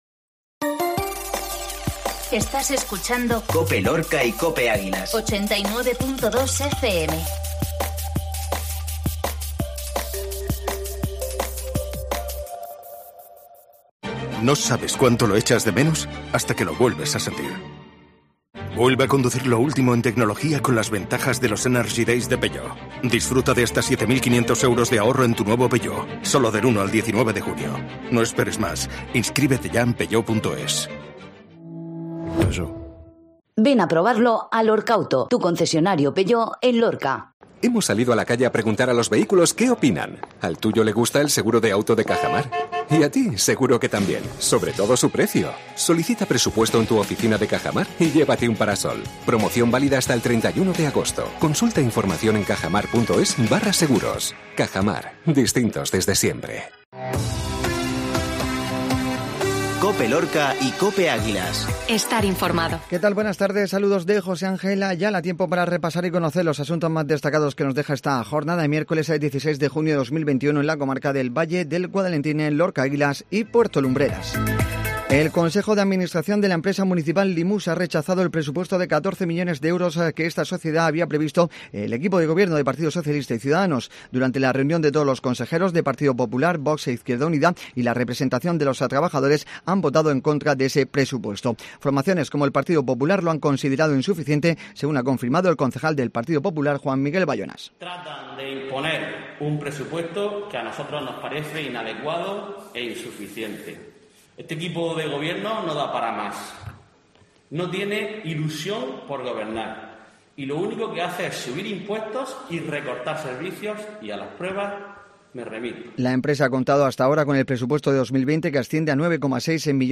INFORMATIVO MEDIODÍA MIÉRCOLES